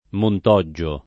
[ mont 0JJ o ]